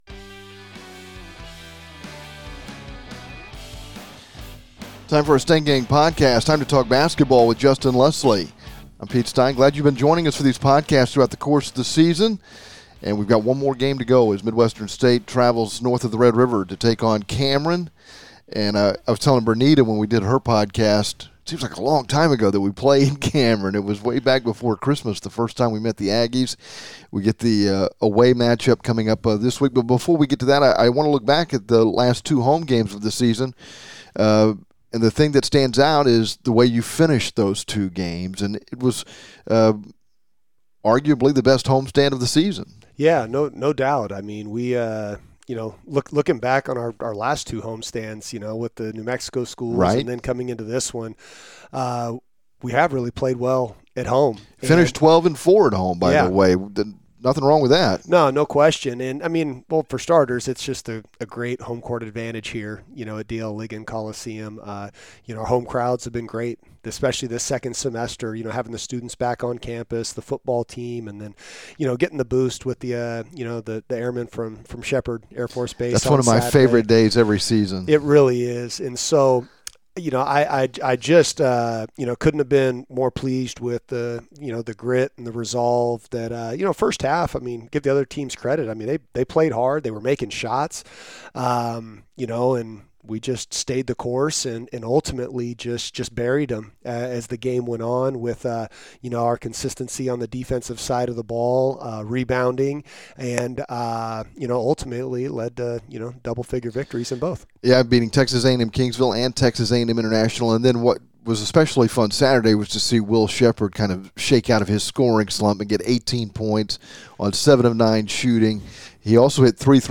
A candid conversation